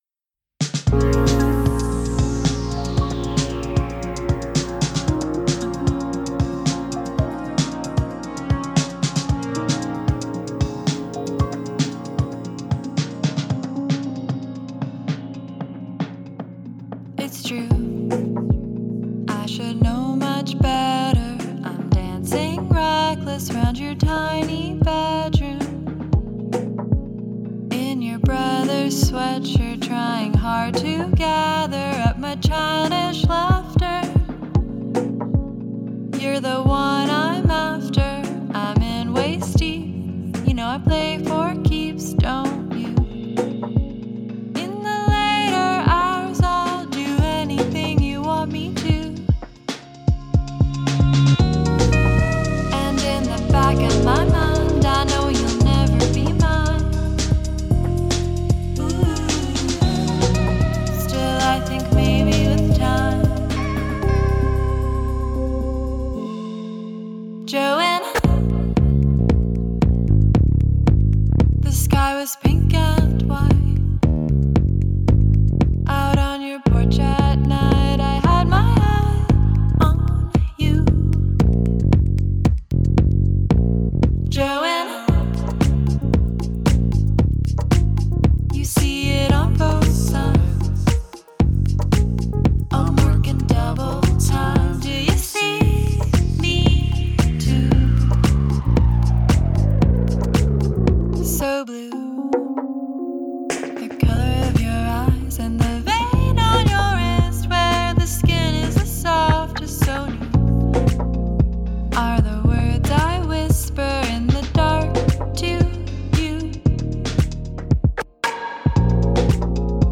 local folk singer
American bedroom pop artist
Danish singer songwriter